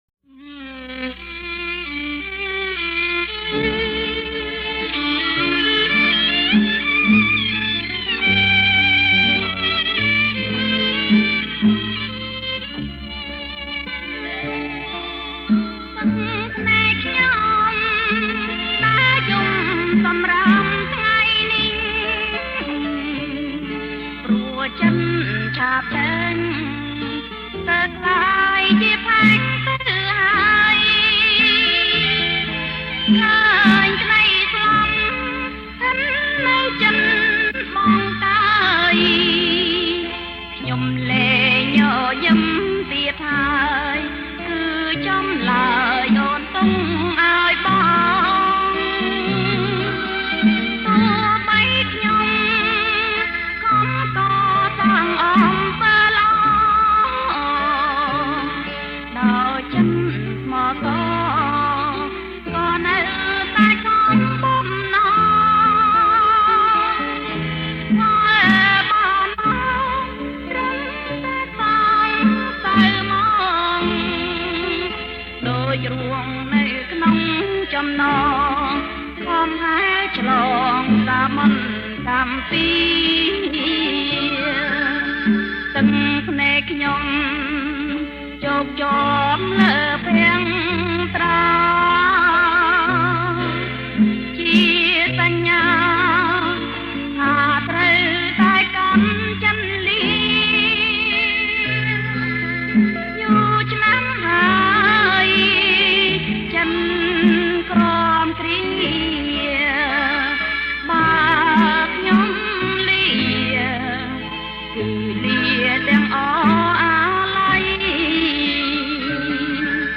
• ប្រគំជាចង្វាក់ Bolero Folk